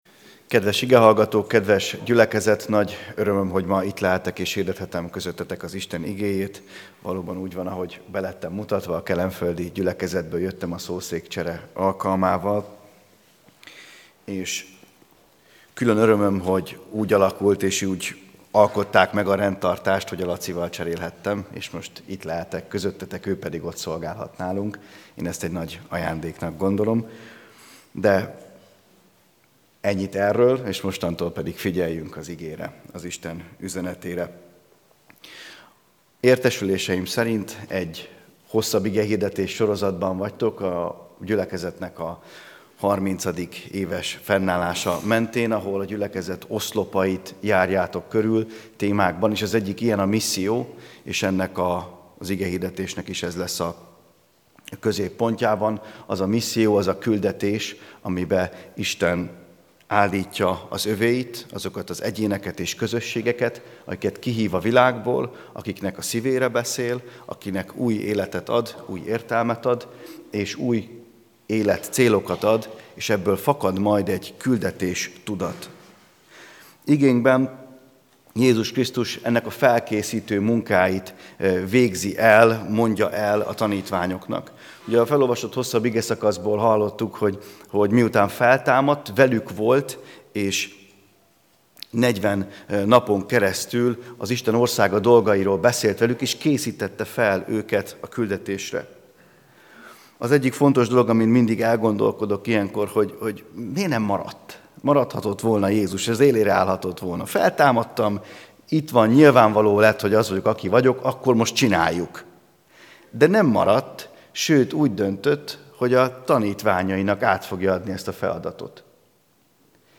AZ IGEHIRDETÉS LETÖLTÉSE PDF FÁJLKÉNT AZ IGEHIRDETÉS MEGHALLGATÁSA